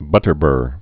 (bŭtər-bûr)